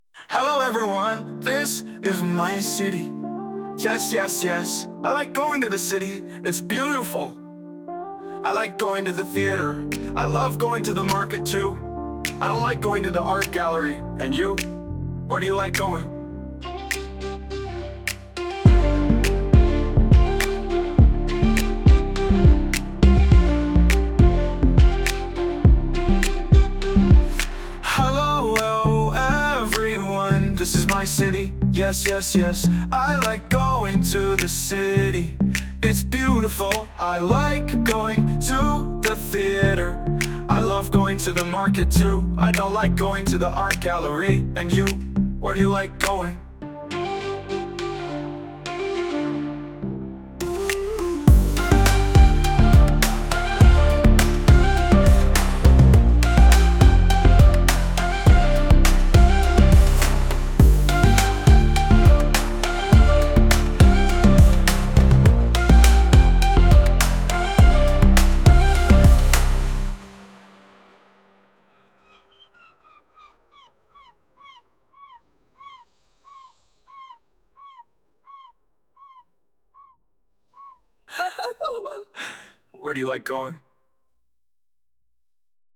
1.1 Song | Where do you live?